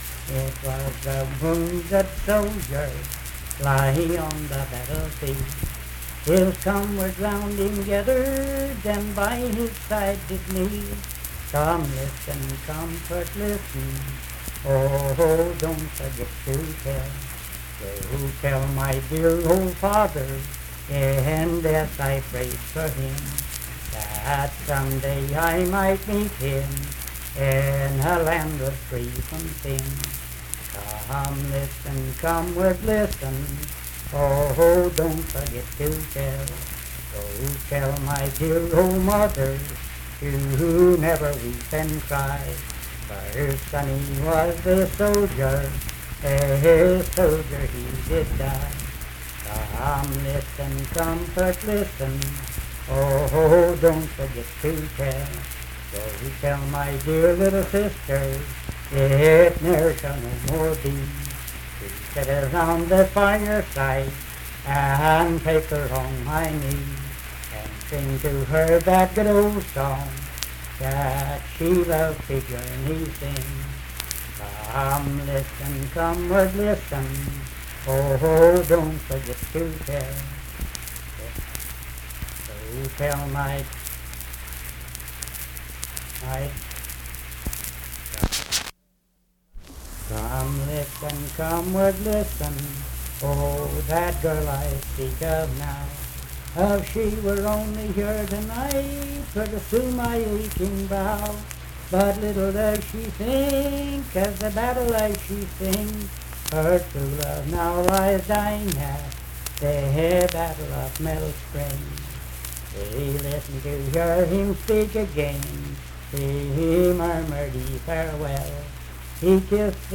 Wounded Soldier - West Virginia Folk Music | WVU Libraries
Unaccompanied vocal music performance
Verse-refrain 12(4).
Voice (sung)